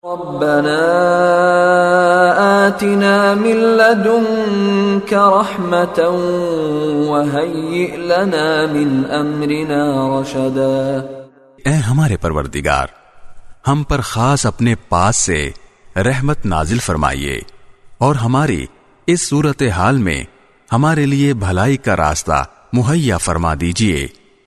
Rabbana Atina Min Ladunka Rahmatan - Audio Dua in Arabic With Urdu Translation
His melodious voice and impeccable tajweed are perfect for any student of Quran looking to learn the correct recitation of the holy book.